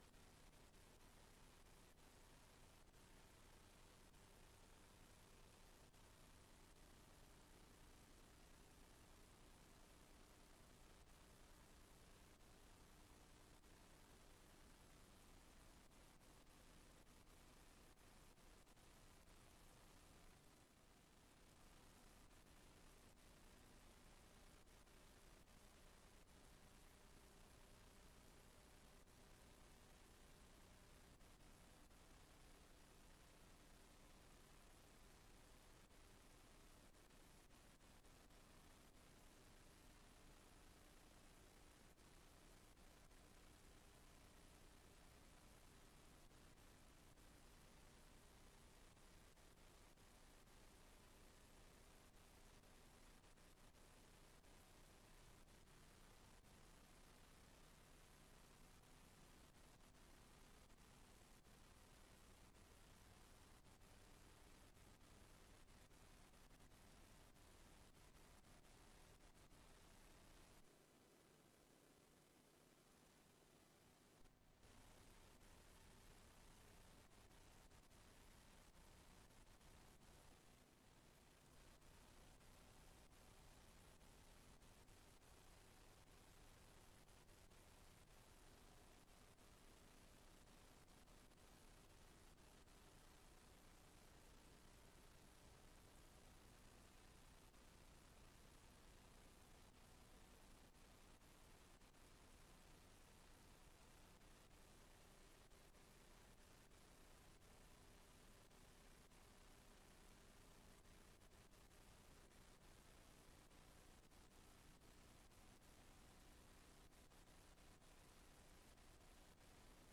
Raadsbijeenkomst 04 juni 2025 19:30:00, Gemeente Tynaarlo
Locatie: Raadszaal